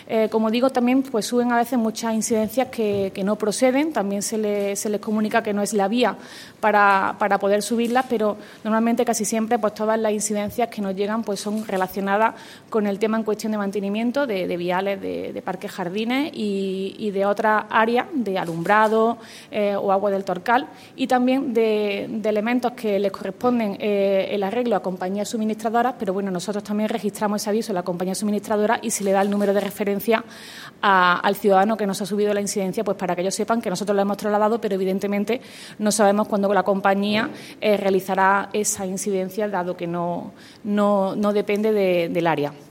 Y precisamente para poder continuar con ese cometido, el Área de Mantenimiento del Ayuntamiento de Antequera ha llevado a cabo una actualización completa de dicha aplicación tal y como ha confirmado en rueda de prensa la teniente de alcalde delegada de Mantenimiento, Teresa Molina.
Cortes de voz